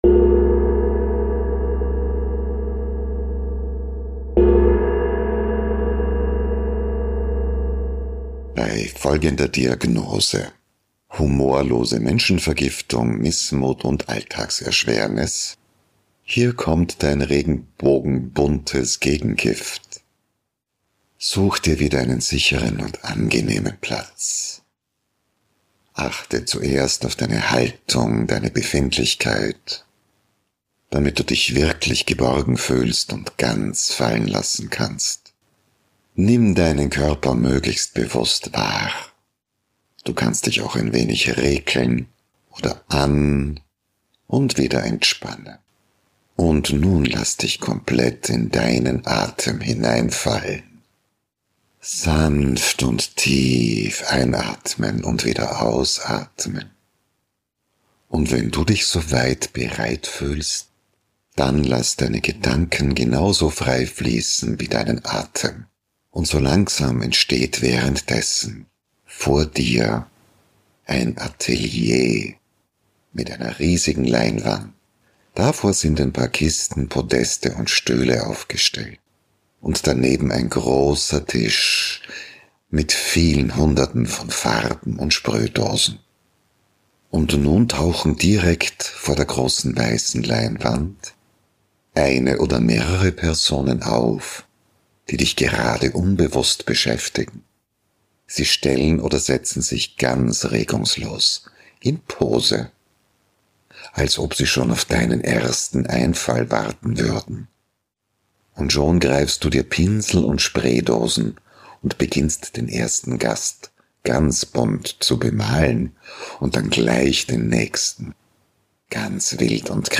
Diese Meditation ist ein bunter Energie-Boost für deine Seele.